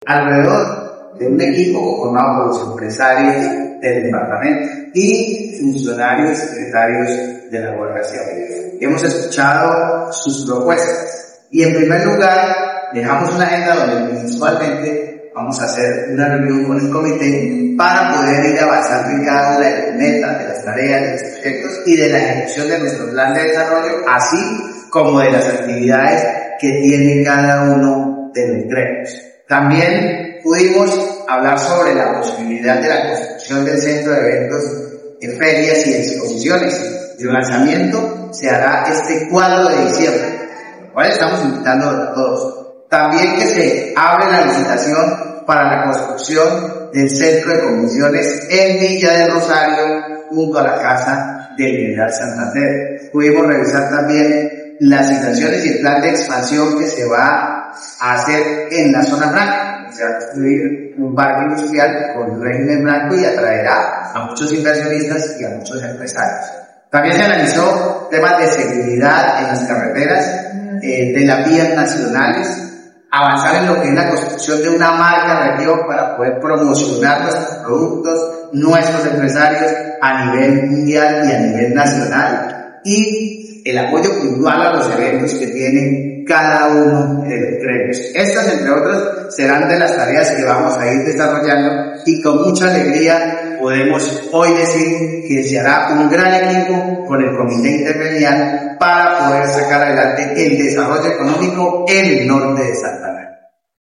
1. Audio del gobernador William Villamizar
Audio-del-gobernador-William-Villamizar.mp3